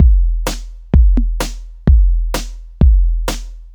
• low pitched techno kick drum.wav
low_pitched_techno_kick_drum_5eL.wav